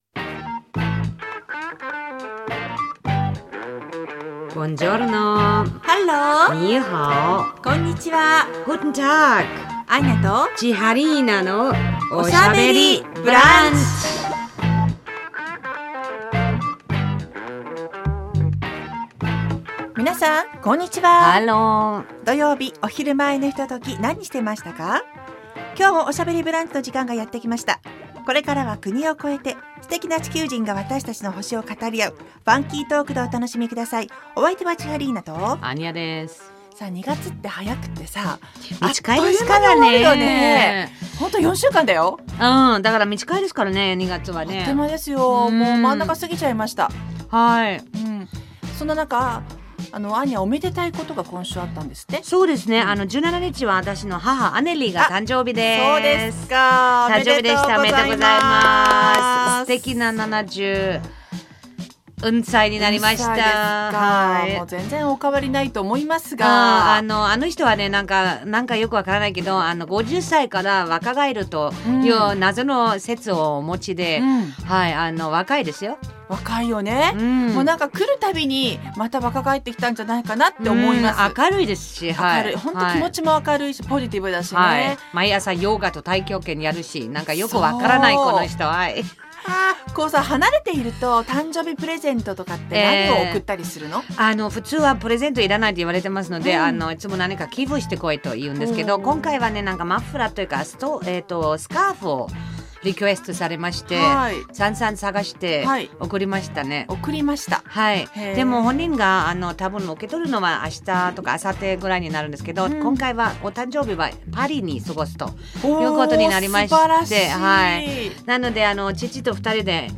放送された内容を一部編集してお送りします。